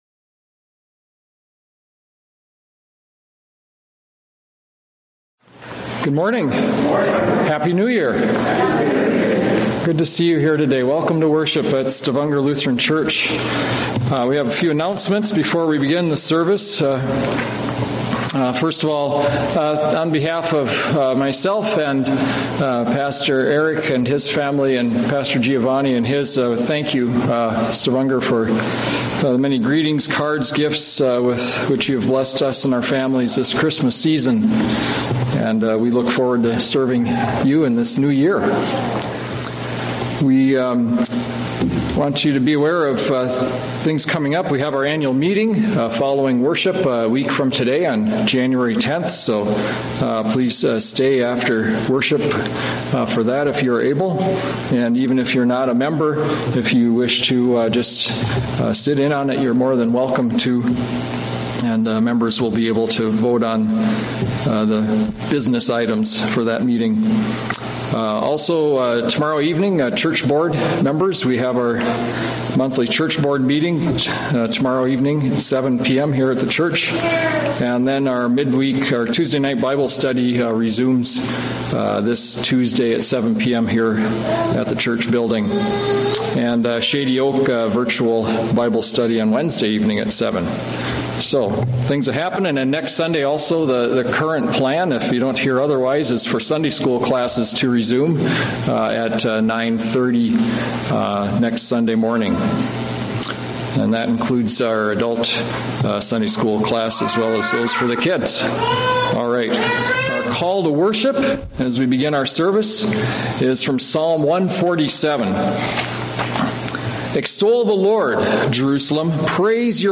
A message from the series "Sunday Worship." Cornerstone - Matthew 21:33-46